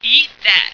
flak_m/sounds/female1/int/F1eatthat.ogg at d2951cfe0d58603f9d9882e37cb0743b81605df2